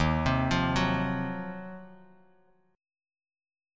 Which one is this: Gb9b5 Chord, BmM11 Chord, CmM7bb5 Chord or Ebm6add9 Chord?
Ebm6add9 Chord